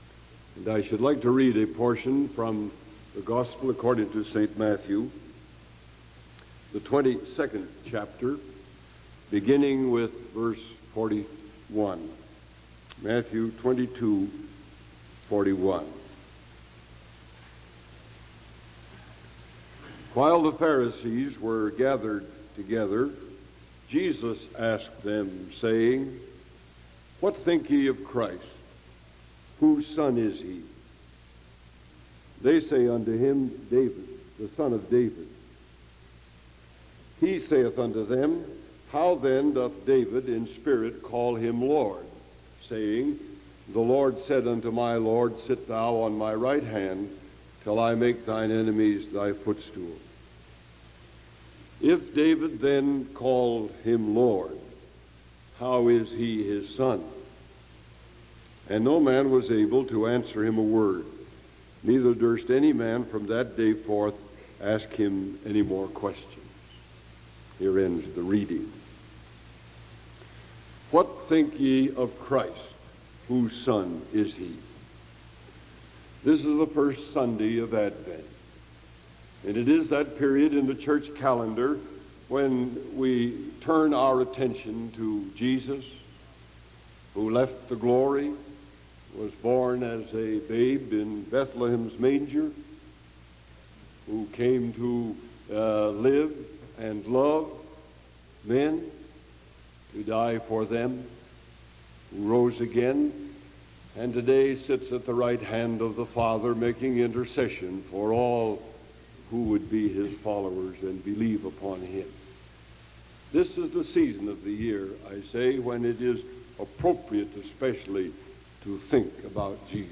Sermon December 1st 1974 AM